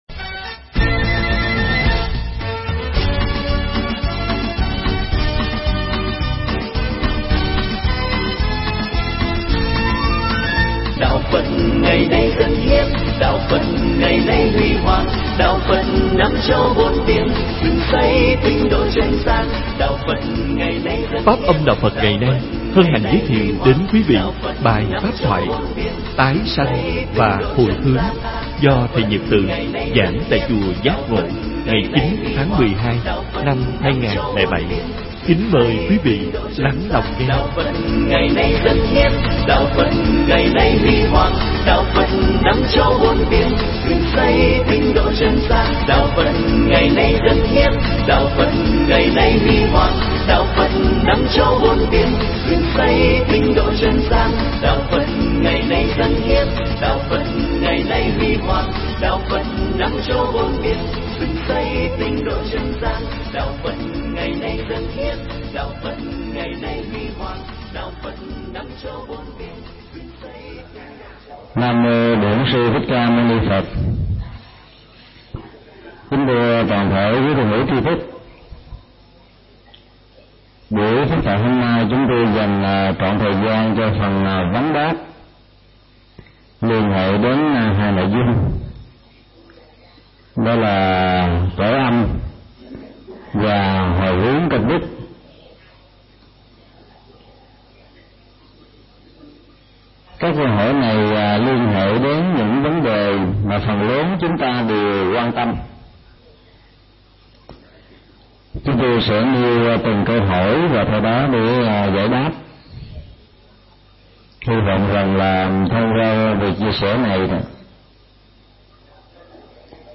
Tải mp3 Thuyết pháp Tái sinh và hồi hướng được thầy Thích Nhật Từ giảng tại Chùa Giác Ngộ, ngày 09 tháng 12 name 2007